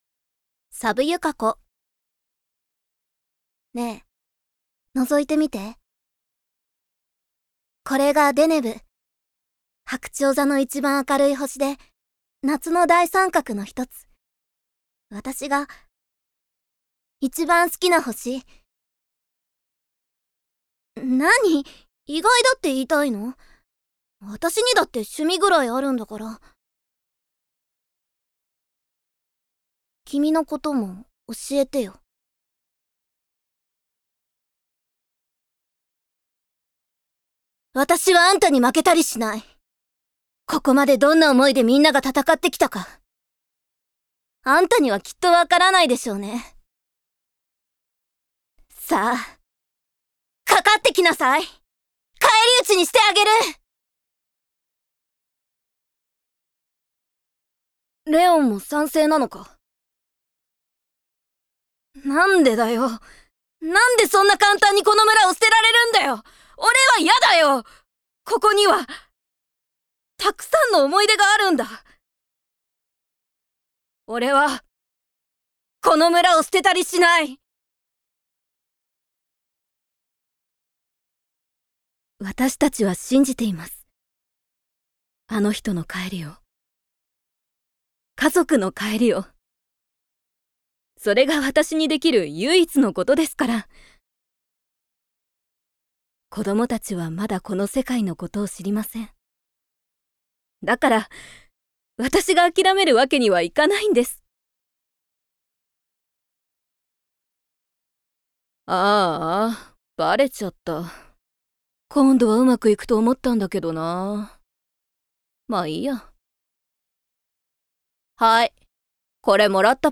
誕生日： 2月7日 血液型： A型 身 長： 163cm 出身地： 石川県 趣味・特技： 旅行・史跡巡り・スキー・古武道 資格： 普通自動車第一種運転免許 音域： B♭～C 方言： 金沢弁
VOICE SAMPLE